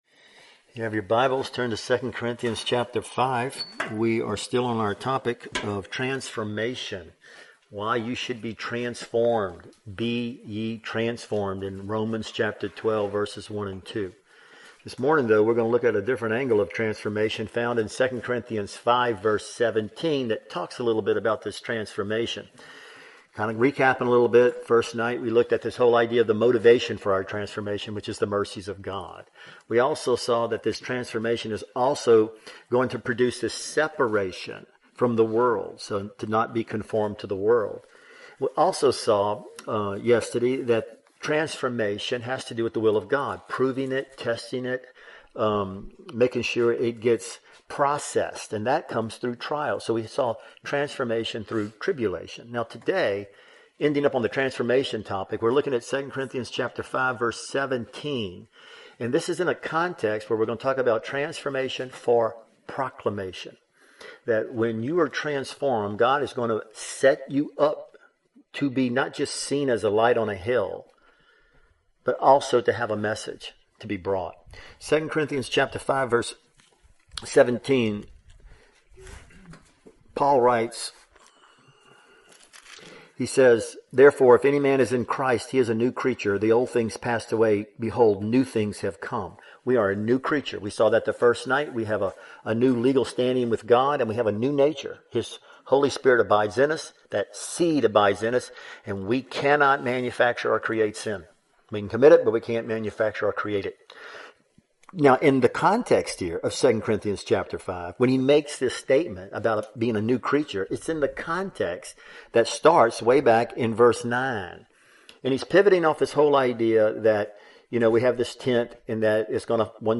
2019 Category: Full Sermons God is a King and He has transformed us to be His ambassadors.